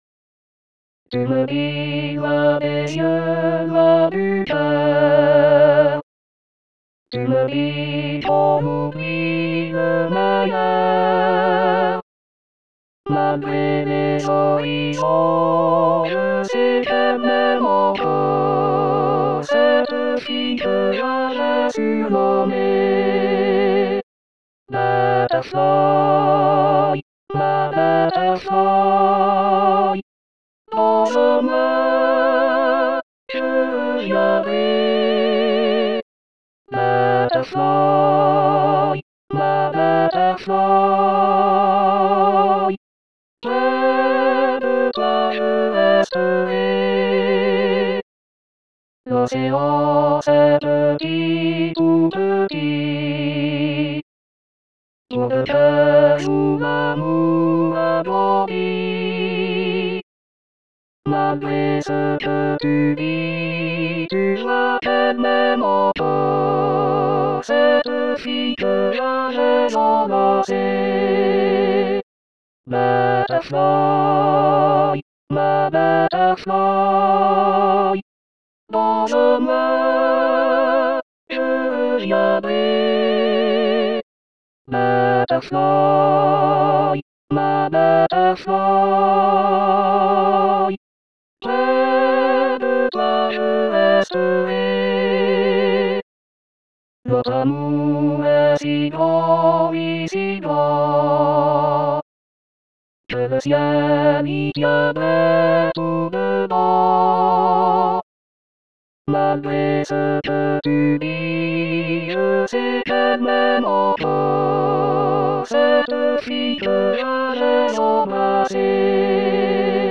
Harmonisation 3 voix
Fichier mp3 avec voix synthétiques.